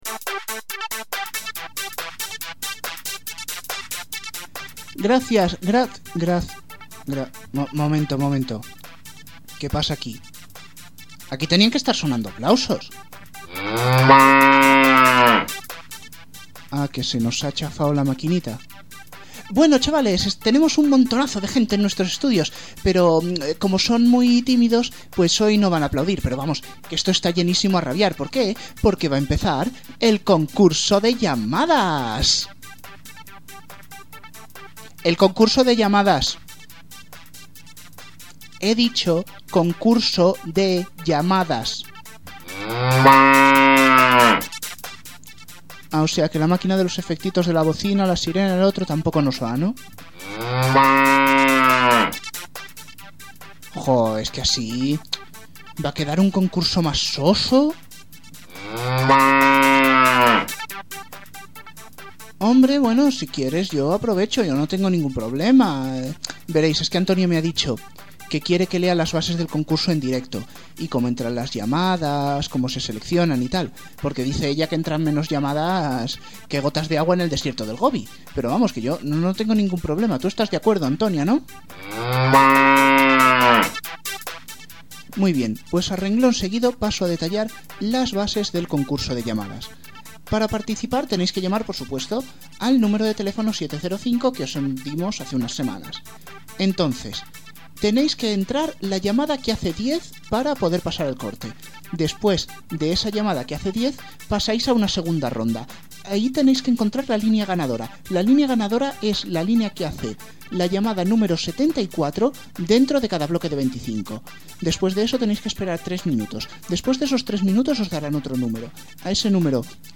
Problemas técnicos, pero las bases del programa, bien claritas…